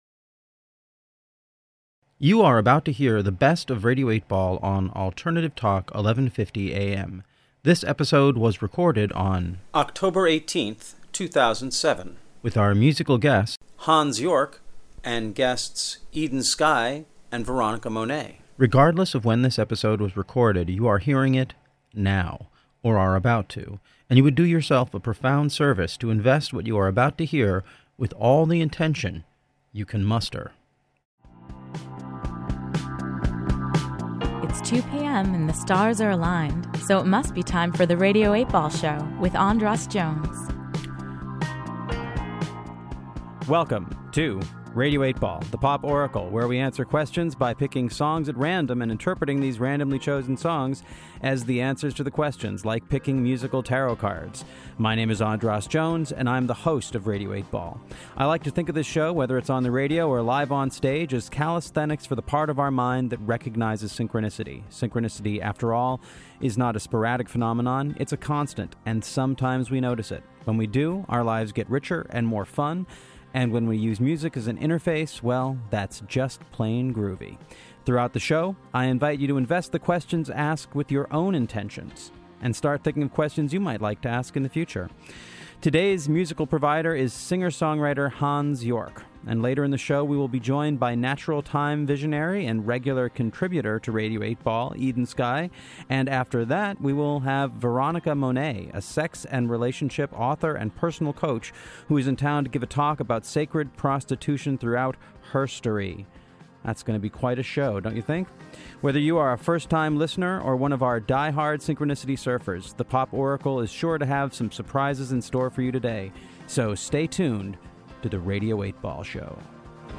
Thus, in 2007 The Radio8Ball Show on Alternative Tall 1150 AM was born. Unlike the KAOS show, the AM broadcast in Seattle (for technical and logistical reasons) was less focused on taking audience calls and more focused on exploring synchronicity with the guests.